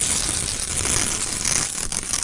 工具 " 粘扣带1
描述：撕下魔术贴（钩环扣）。
Tag: 尼龙搭扣 紧固件